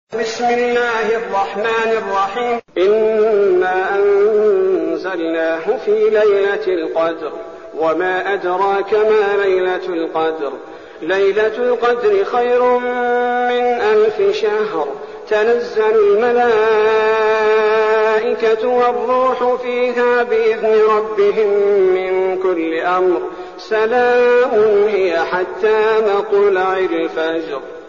المكان: المسجد النبوي الشيخ: فضيلة الشيخ عبدالباري الثبيتي فضيلة الشيخ عبدالباري الثبيتي القدر